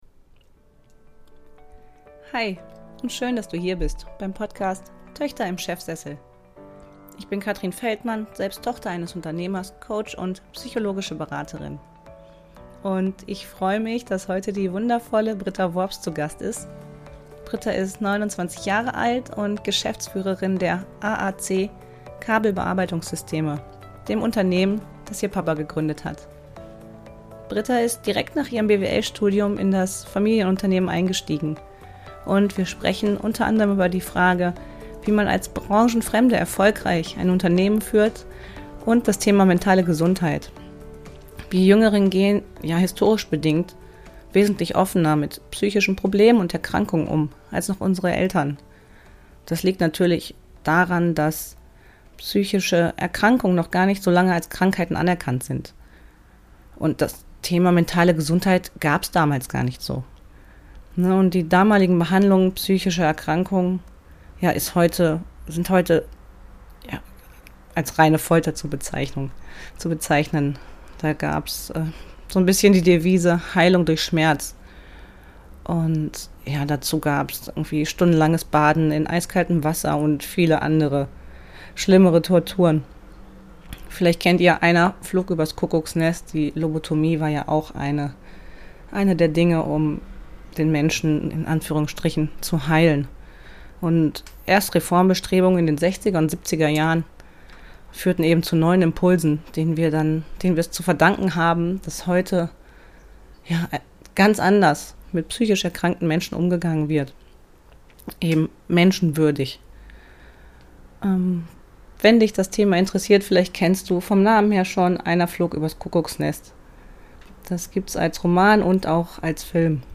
Mentale Gesundheit – Interview